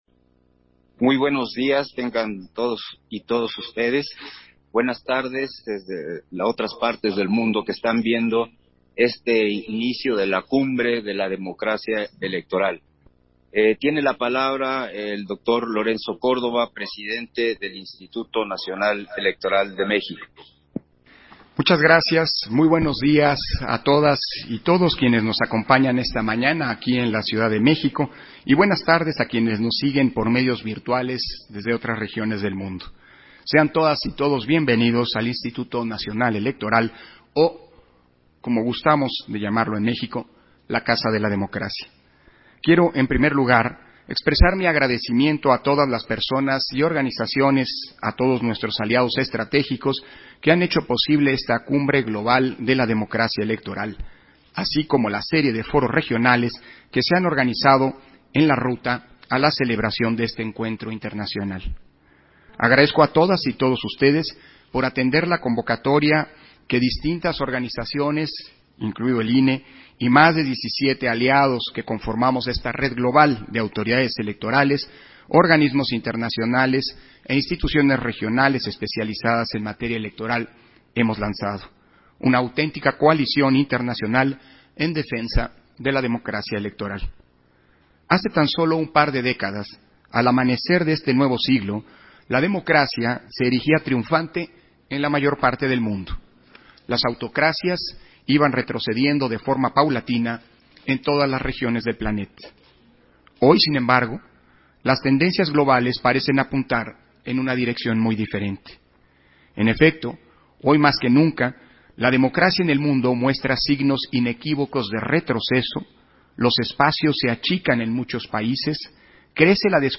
Intervención de Lorenzo Córdova, en la inauguración de la Cumbre Global de la Democracia Electoral